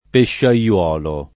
pesciaiolo [peššaL0lo] s. m. — oggi lett. pesciaiuolo [